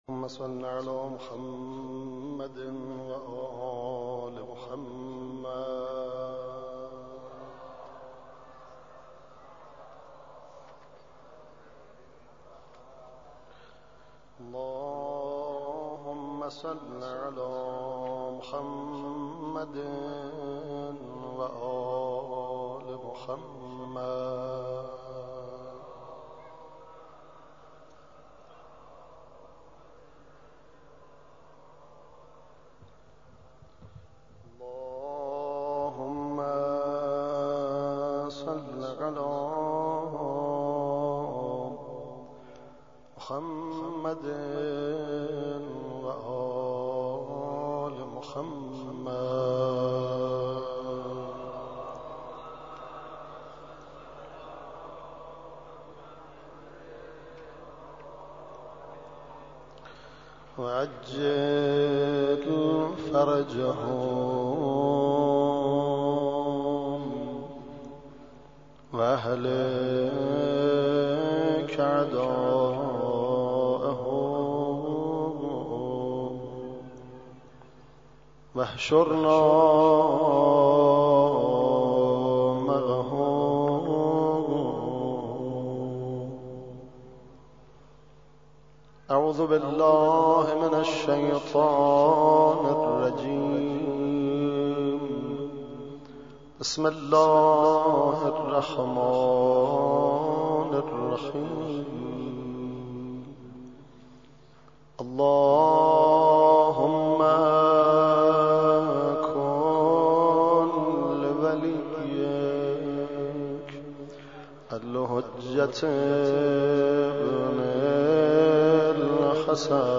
دعای افتتاح